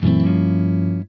SLIDECHRD2.wav